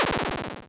se_kill.wav